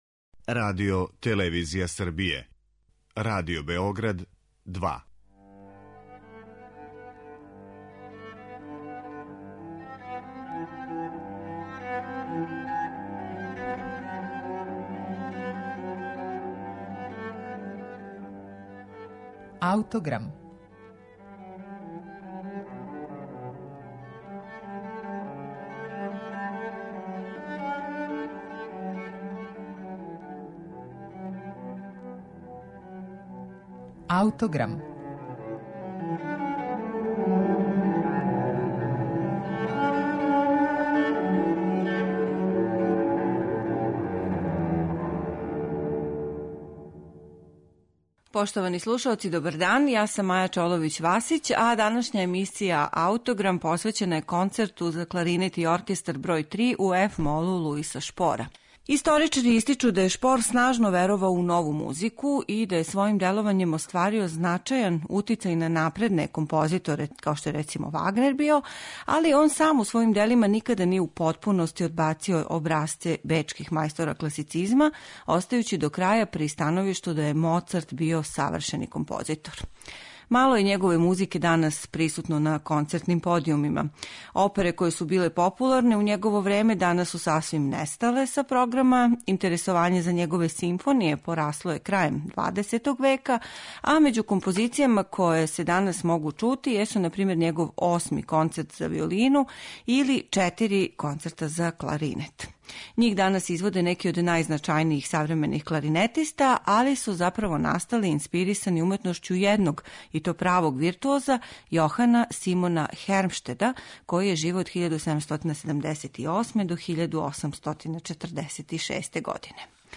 Слушаћете Трећи концерт за кларинет и оркестар, Луиса Шпора
Концерт ће извести француски уметник Пол Мејер